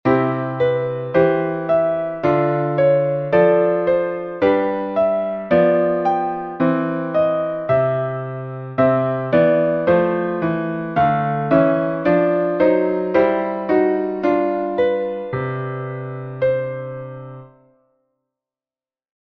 Hilfston - Notenbeispiele